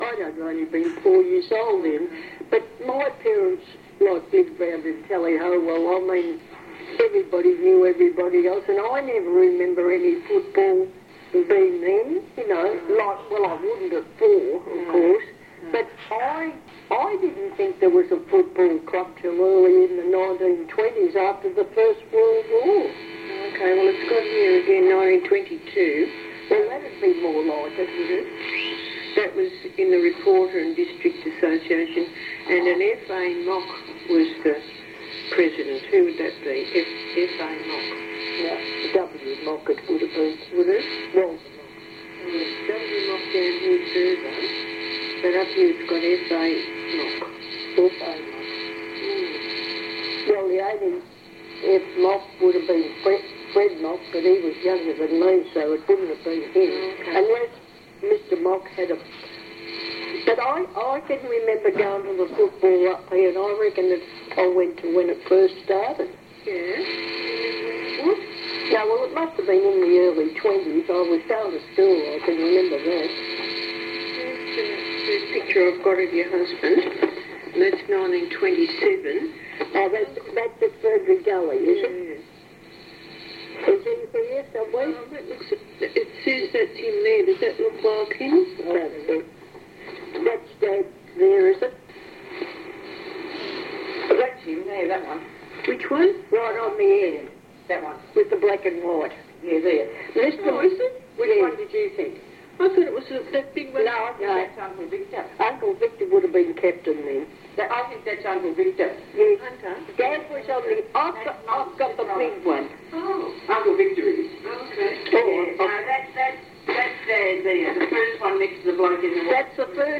Audio - Oral History